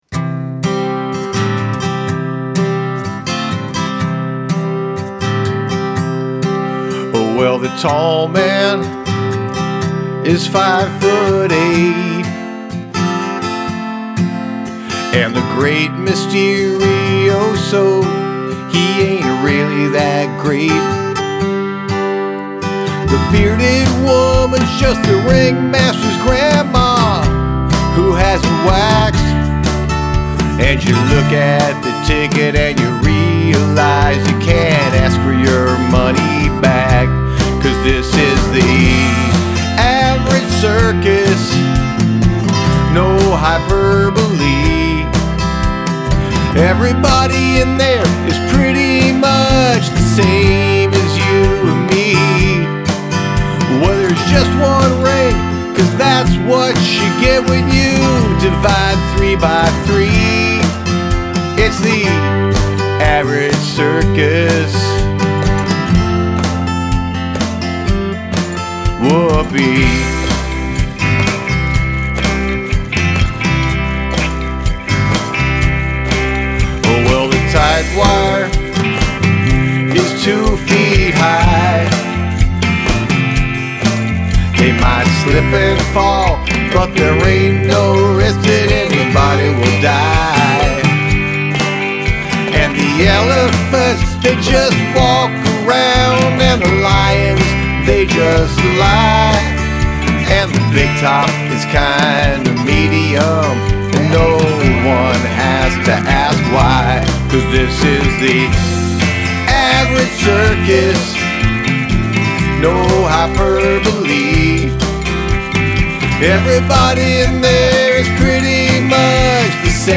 Use something from your garbage bin as an instrument
The plinky (I assume from the garbage) instrument is cool.